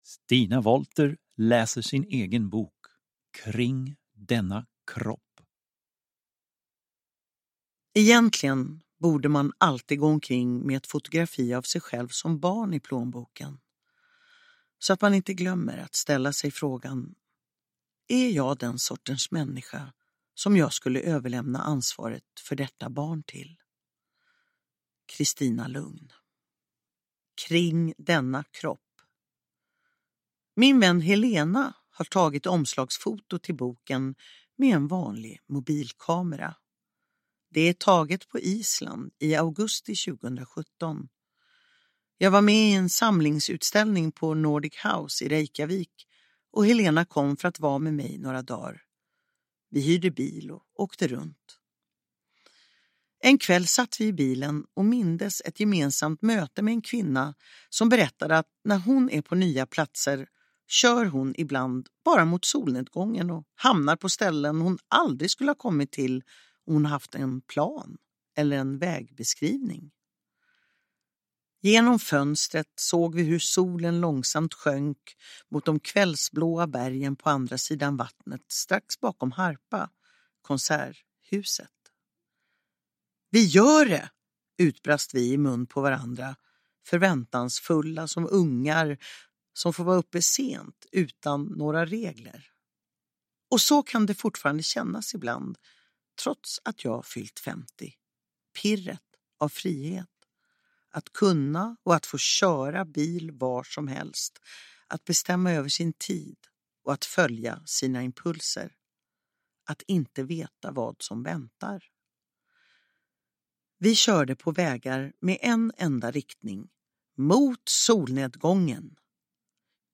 Uppläsare: Stina Wollter
Ljudbok